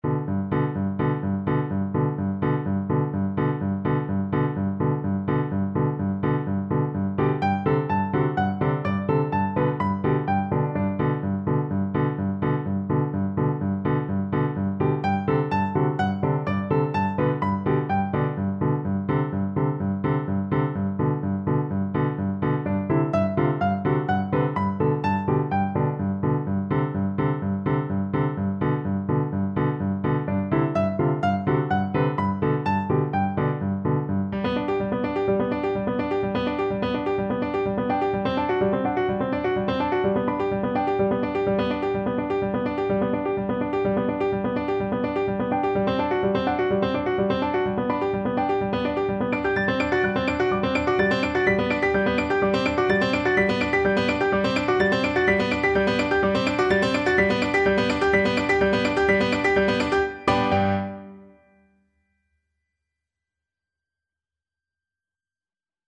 4/4 (View more 4/4 Music)
G major (Sounding Pitch) (View more G major Music for Recorder )
Allegro moderato (=126) (View more music marked Allegro)
Classical (View more Classical Recorder Music)